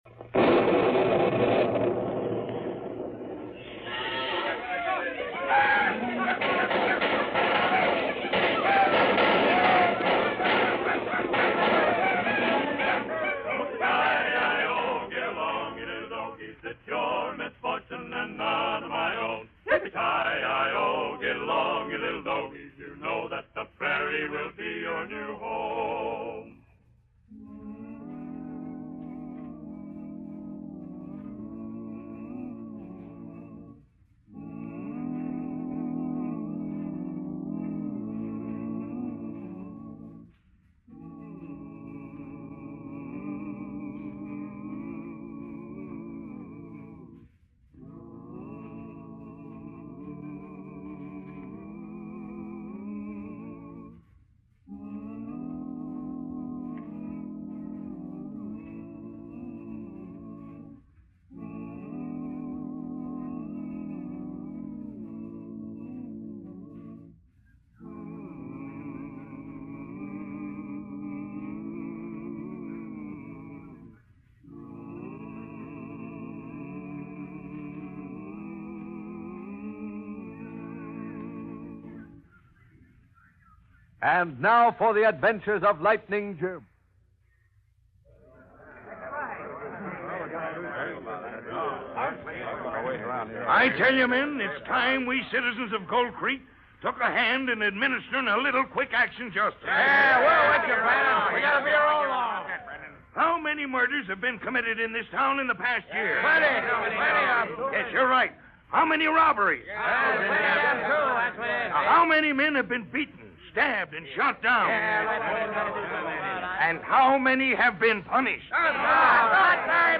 "Lightning Jim" was a popular old-time radio show that featured the adventures of U.S. Marshal Lightning Jim Whipple. - The character of Lightning Jim, along with his trusty horse Thunder and deputy Whitey Larson, became iconic figures in Western radio drama.